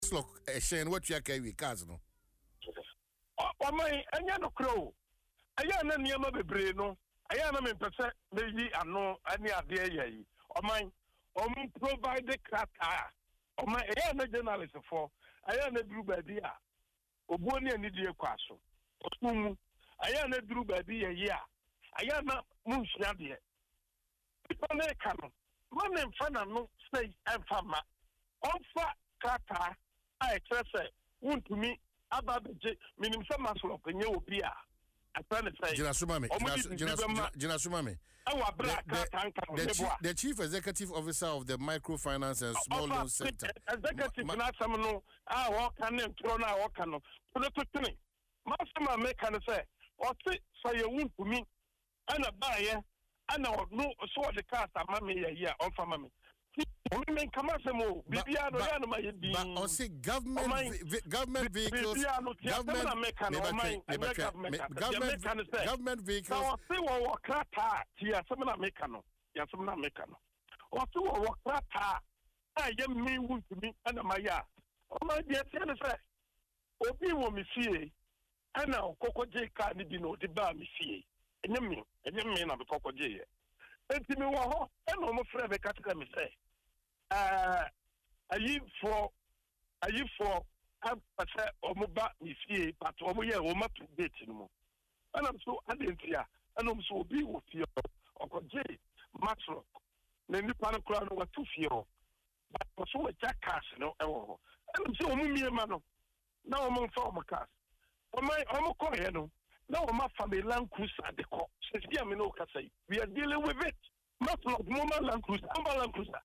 Speaking on Adom FM’s Dwaso Nsem morning show, Wontumi dismissed the allegations as baseless and called on those making the claims to provide evidence.